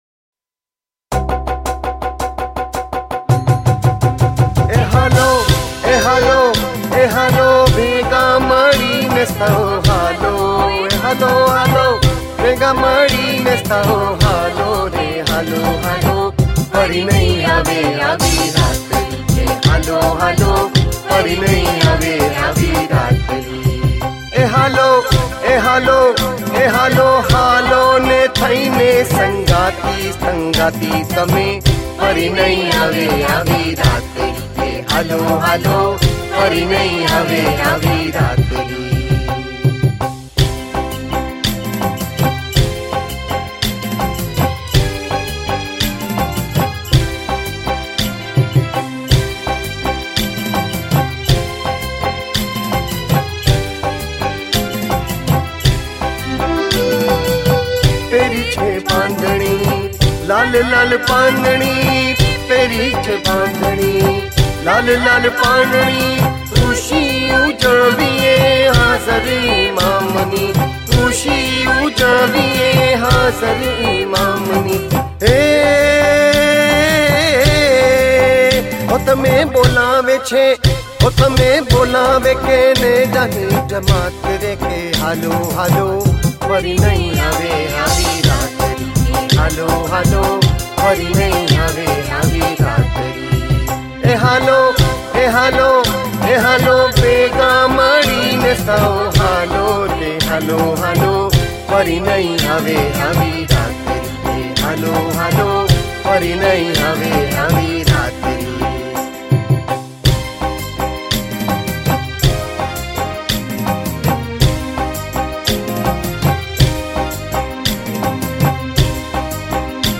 Raas